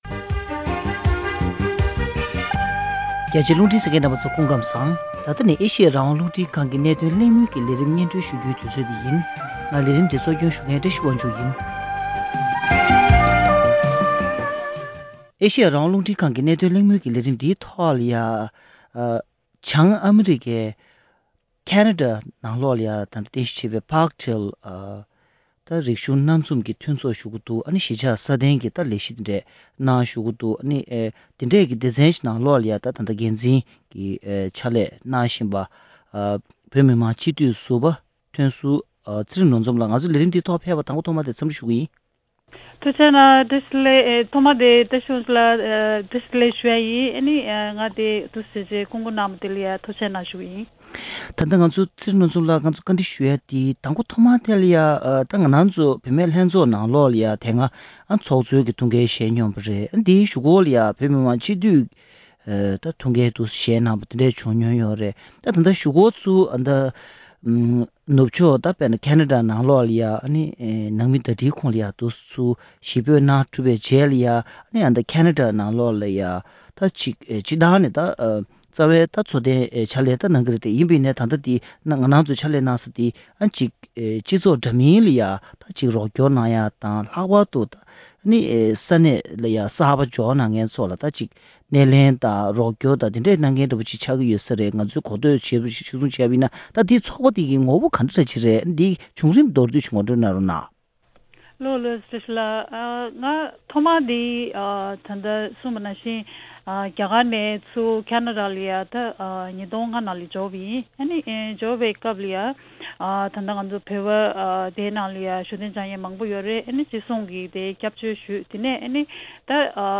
ཁེ་ན་ཌའི་ Parkdale Intercultural Associationཞེས་པའི་རིག་གཞུང་སྣ་མང་ཚོགས་པའི་གཞིས་ཆགས་ལས་རིམ་སྐོར་བཀའ་འདྲི་ཞུས་པ།